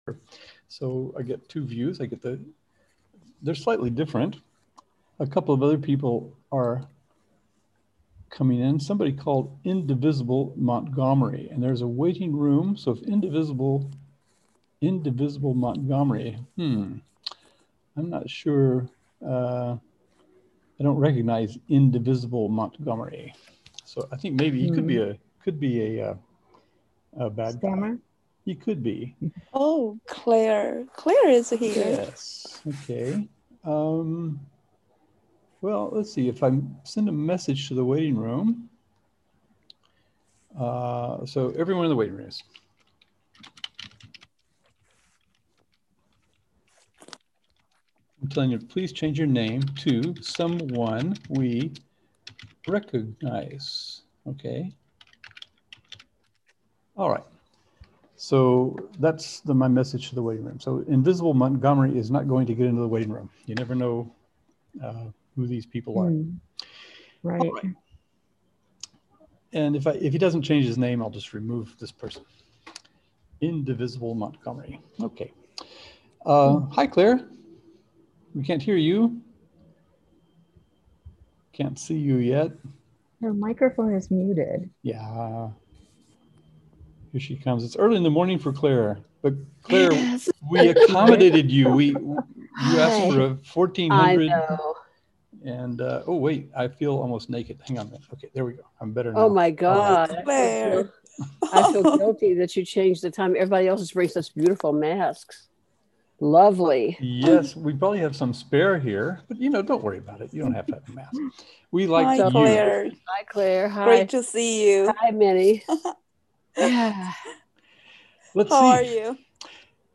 Since it was a spontaneous FUN (frivolous unanticipated nonsense) and open mic event, there was no plan per se, except that we could get together and talk about our intersections in this movement, which began ten years ago (meaning Learning2gether; Webheads began 20 years ago!).